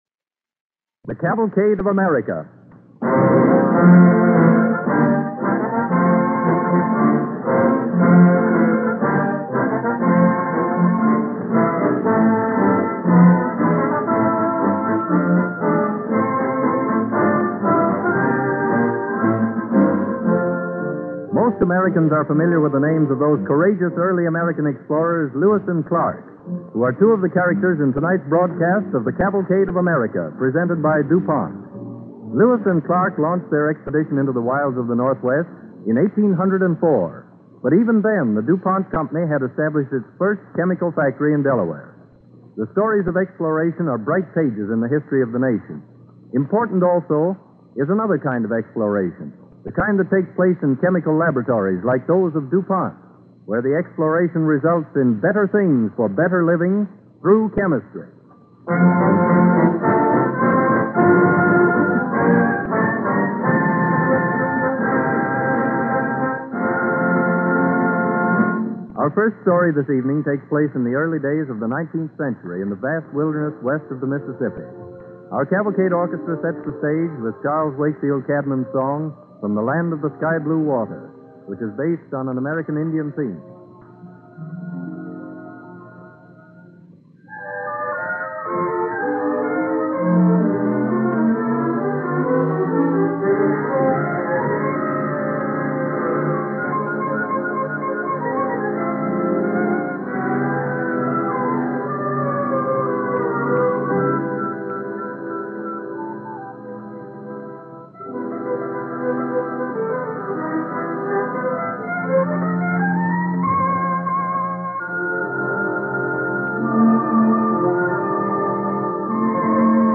With announcer